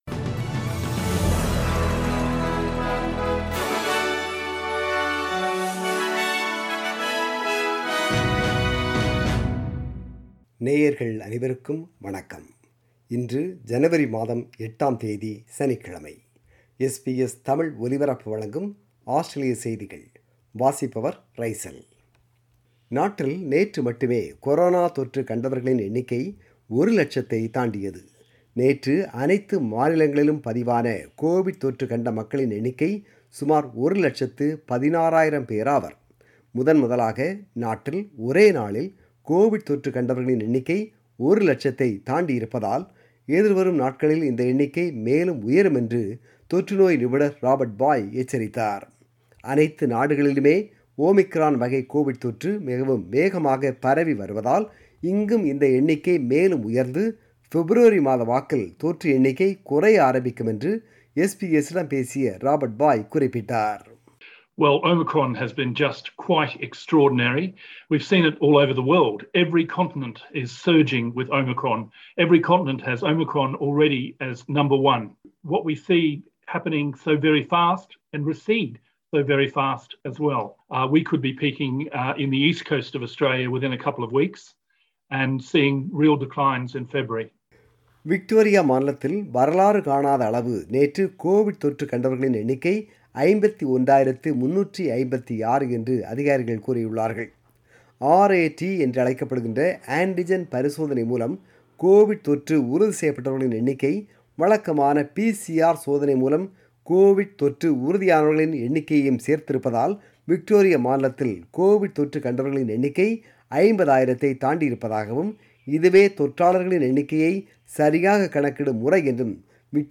Australian News: 8 January 2022 – Saturday